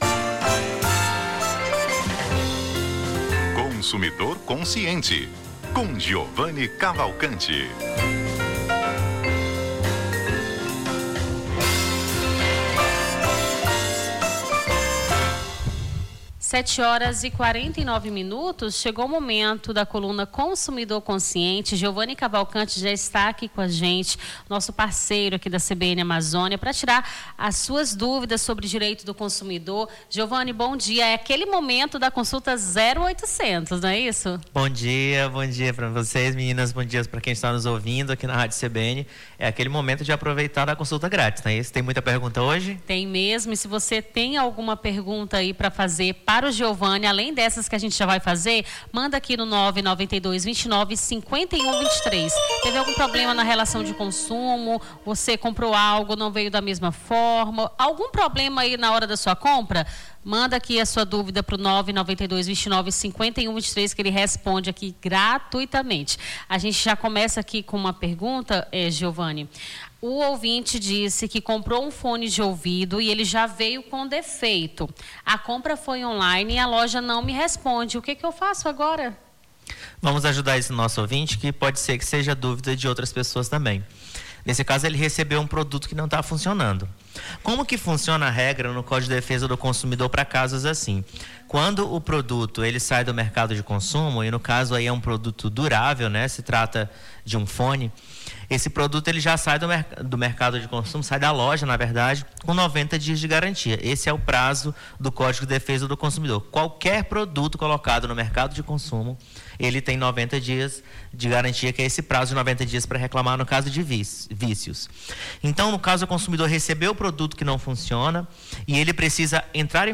Consumidor Consciente: advogado esclarece dúvidas sobre direito do consumidor